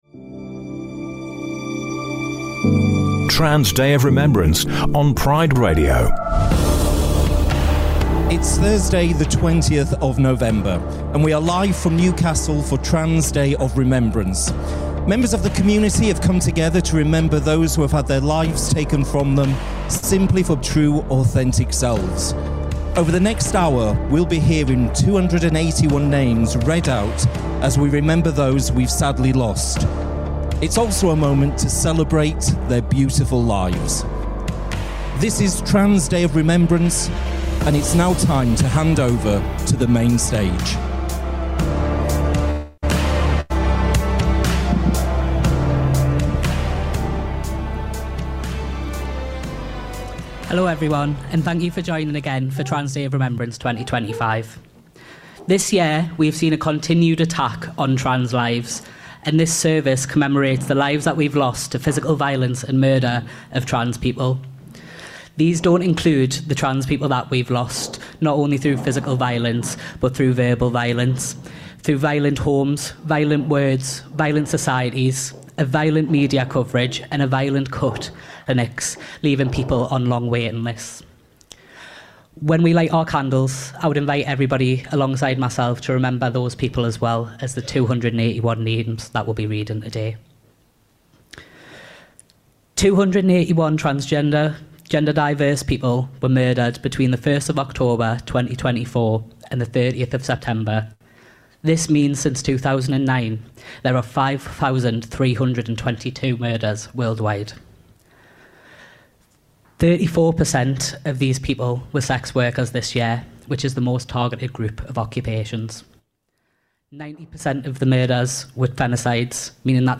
Trans Day of Remembrance 2025 Listen again to the Trans Day of Remembrance vigil broadcast on Thursday 20th November 2025 Trans Day of Remembrance 2025 Broadcast Tuesday 20th November 2025